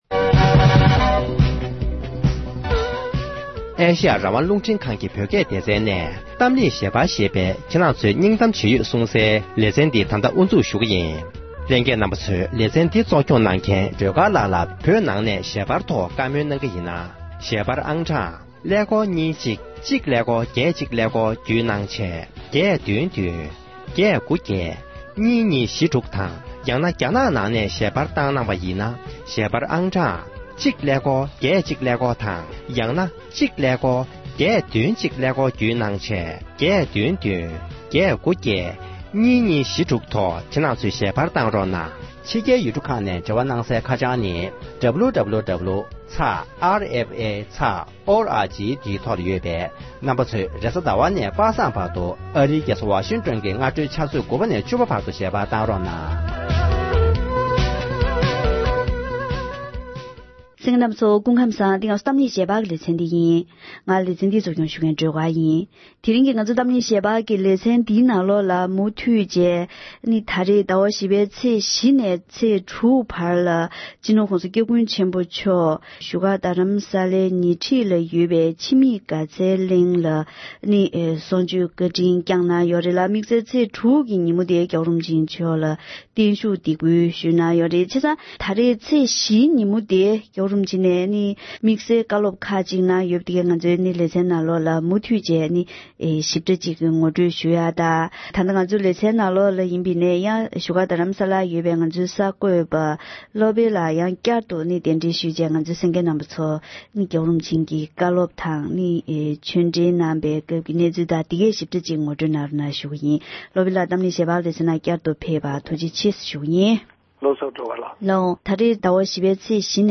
༸གོང་ས་མཆོག་ནས་བཞུགས་སྒར་རྡ་རམ་ས་ལའི་ཉེ་འཁྲིས་སུ་ཡོད་པའི་འཆི་མེད་དགའ་ཚལ་གླིང་དུ་བཀའ་ཆོས་བསྩལ་བ།